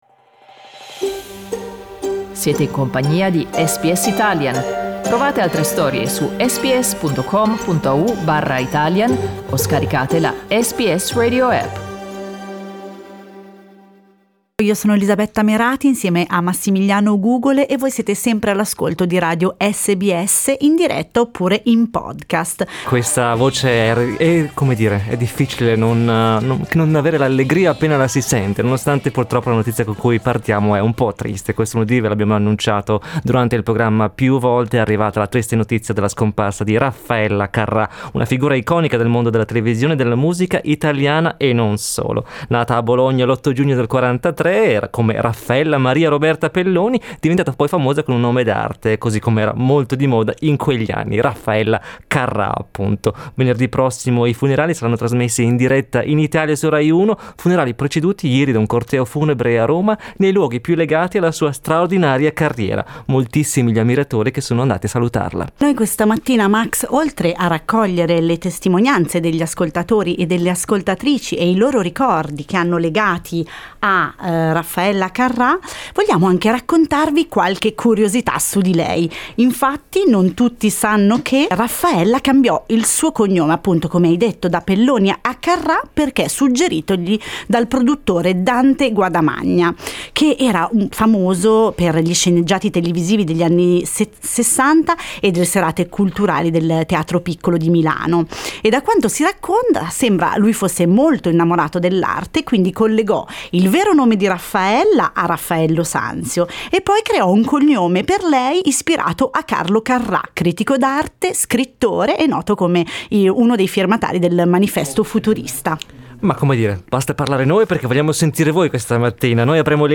Dopo la scomparsa della "più amata dagli italiani", gli ascoltatori e le ascoltatrici di SBS Italian hanno condiviso i loro ricordi di Raffaella Carrà.